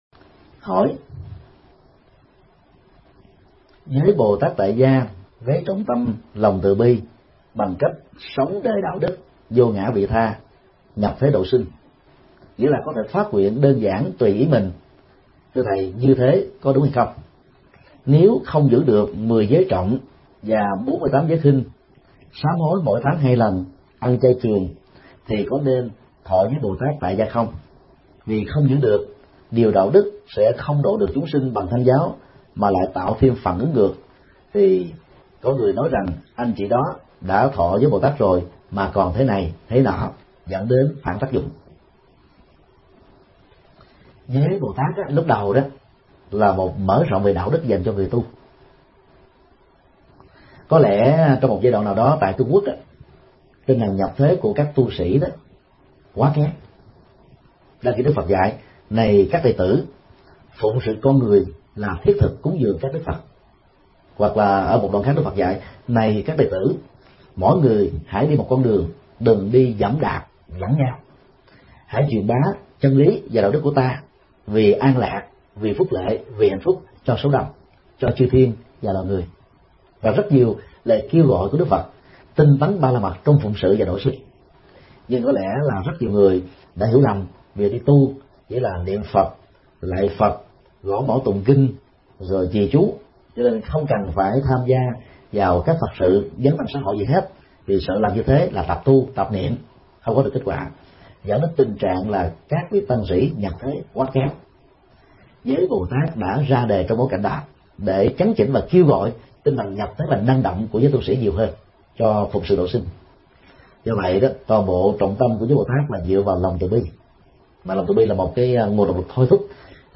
Vấn đáp: Khái niệm và ý nghĩa thọ giới Bồ Tát tại gia